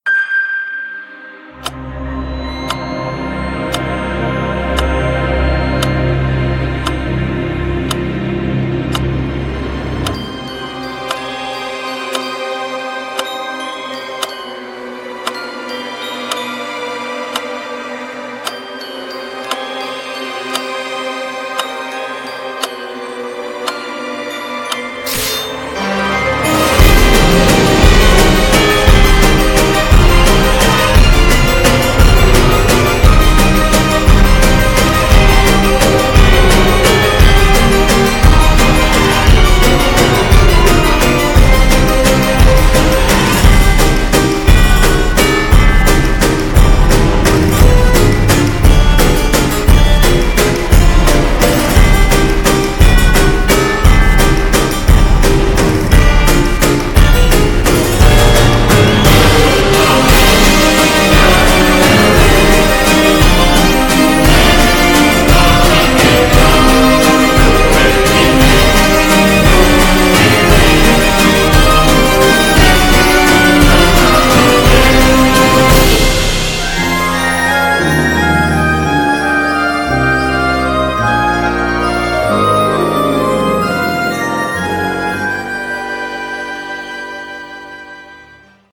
CM風声劇「私のワンダーランド」